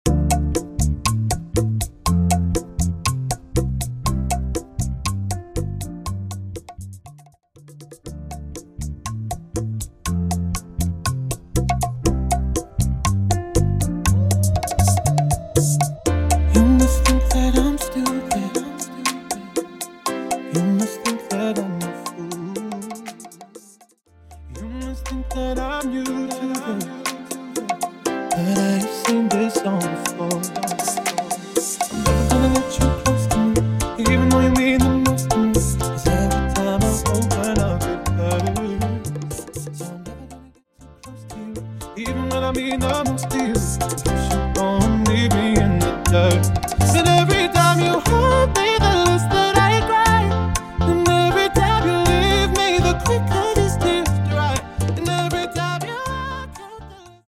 Latin genres
BPM 120 / Bachata